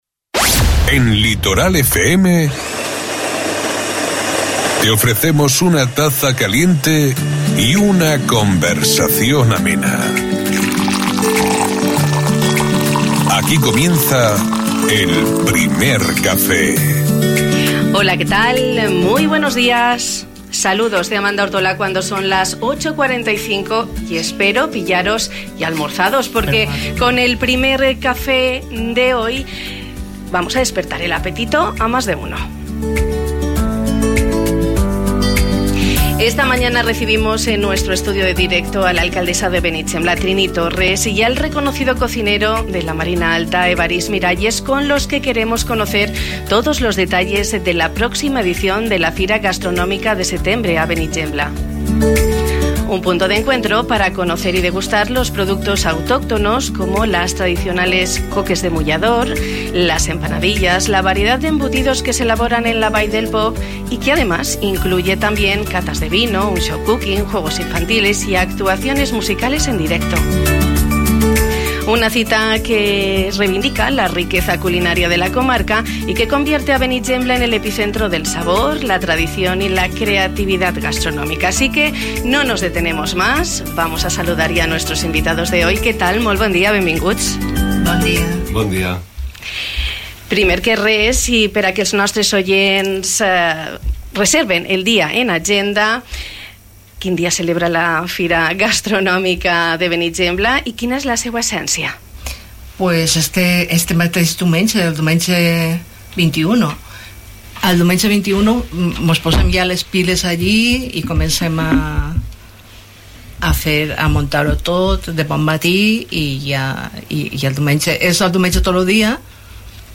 Esta mañana hemos recibido en nuestro estudio de directo a la alcaldesa de Benigembla, Trini Torres